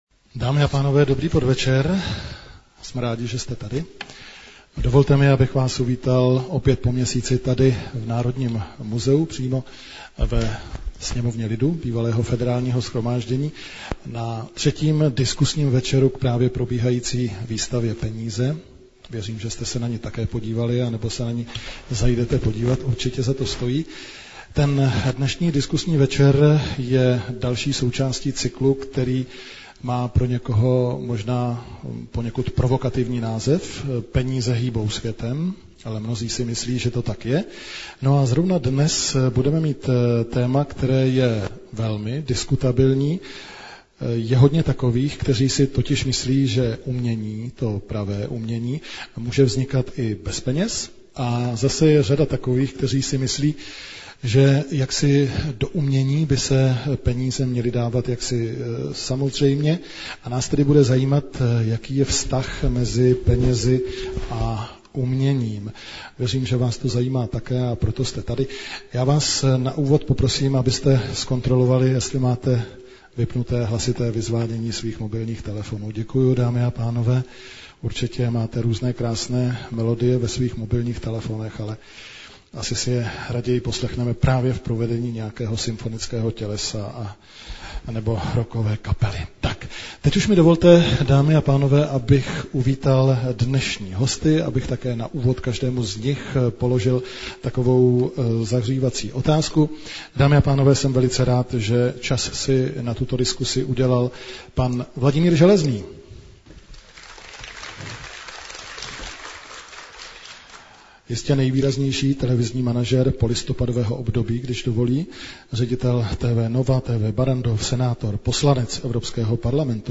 Audiozáznam z debaty Peníze a umění |